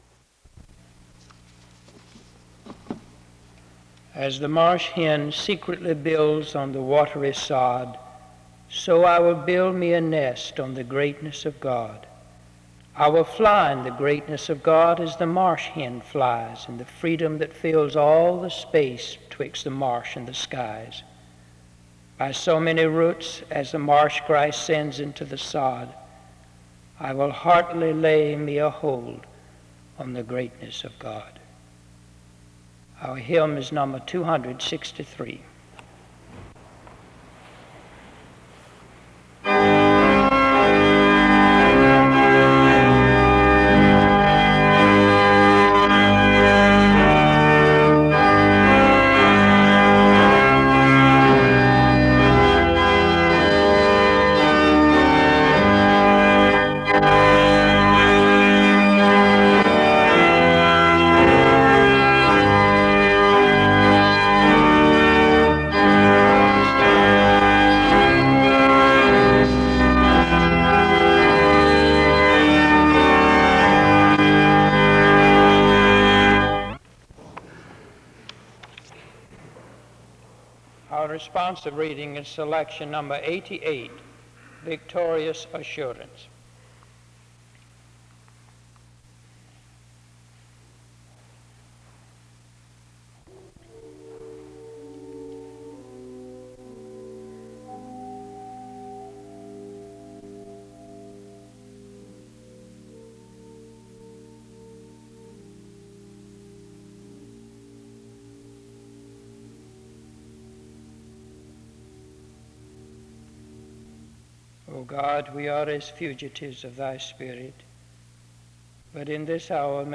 He ends his time in prayer to God (22:15-23:02), and instrumental music ends the service (23:03-23:40).